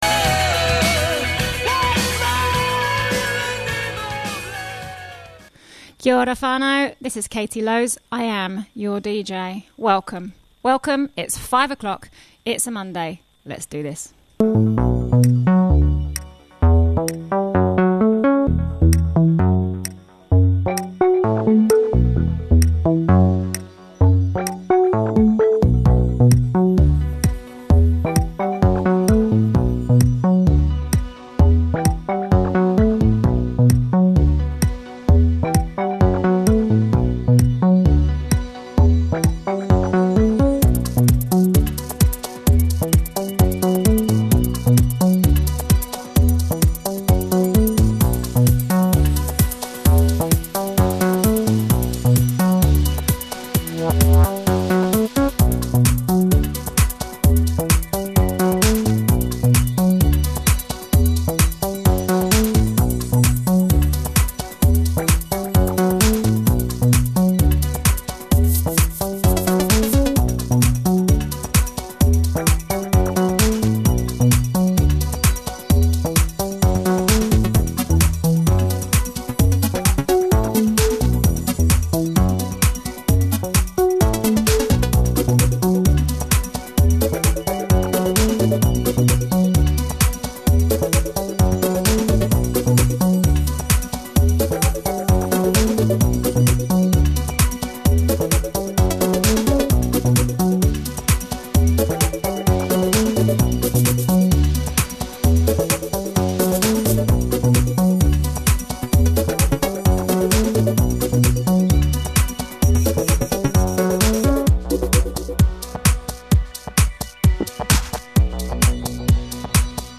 Rolling house whilst the sunsets for the little paradise town.